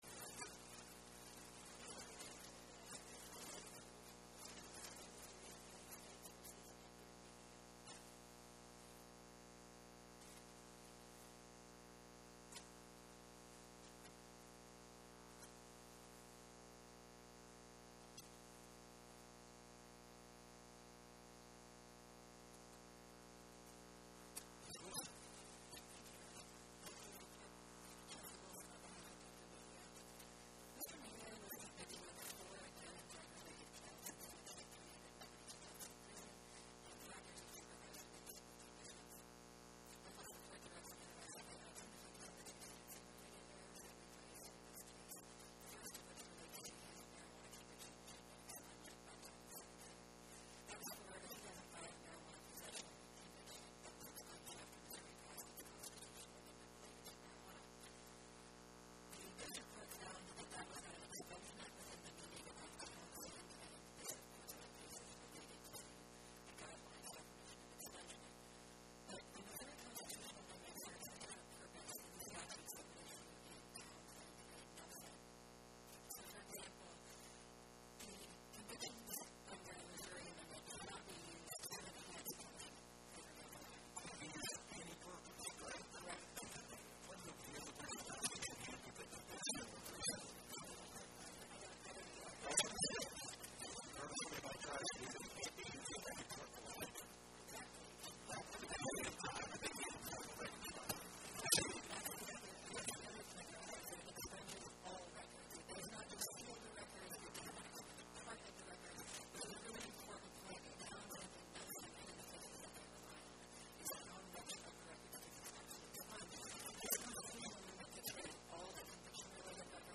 Oral argument argued before the Eighth Circuit U.S. Court of Appeals on or about 11/20/2025